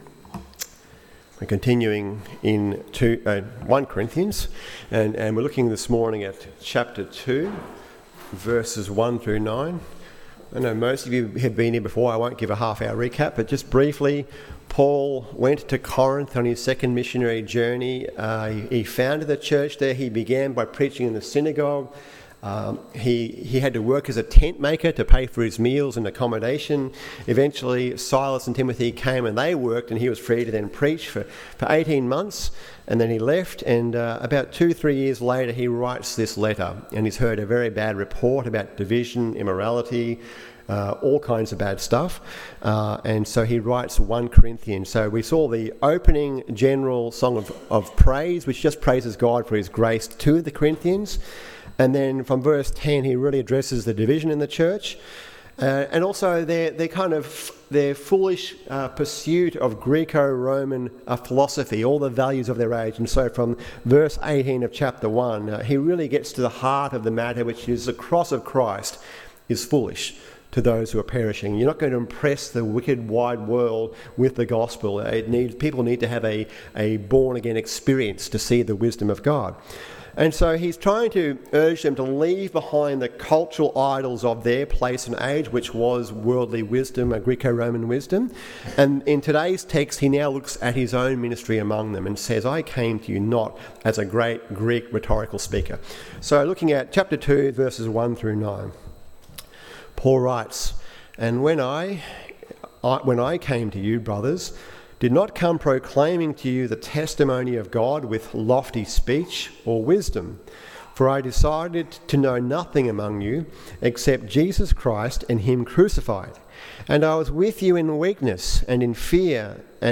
Why We Proclaim ‘The Word Of The Cross’ AM Service